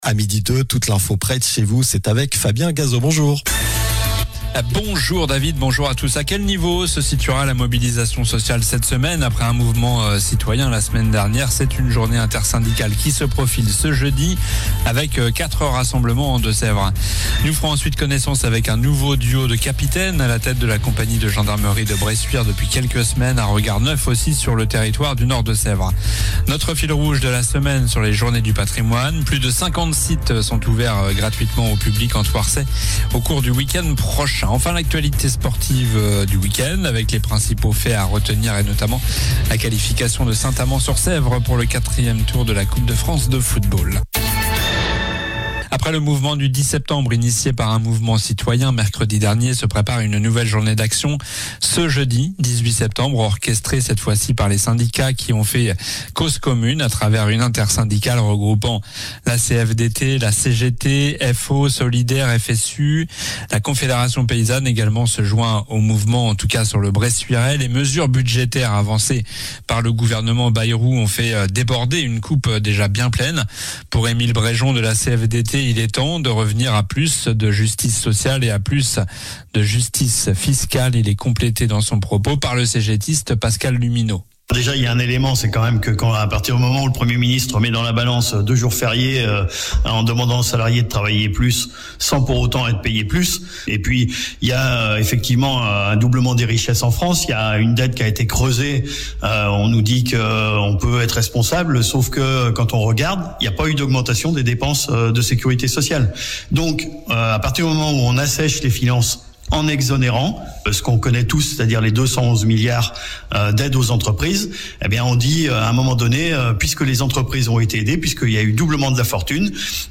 Journal du lundi 15 septembre (midi)